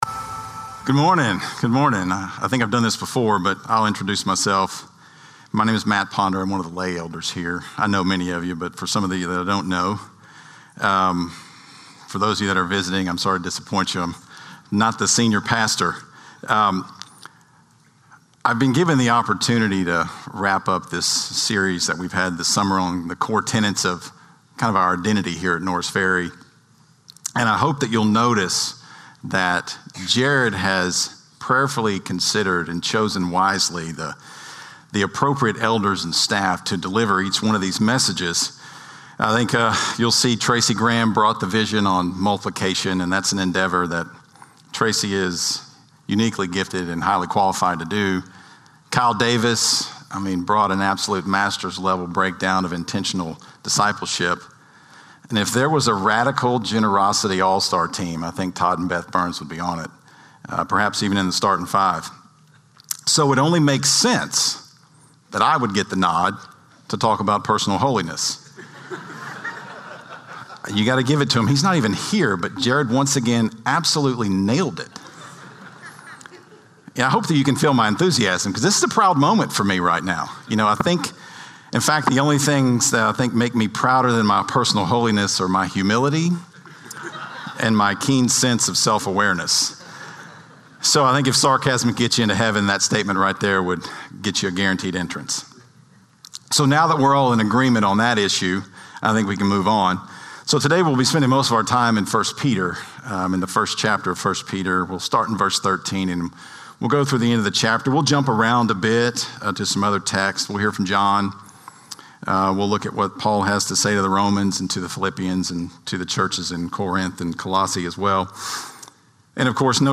Norris Ferry Sermons July 20, 2025 -- Vision 2025 Week 7 -- 1 Peter 1:13-25 Jul 20 2025 | 00:28:56 1x 00:00 / 00:28:56 Subscribe Share Spotify RSS Feed Share Link Embed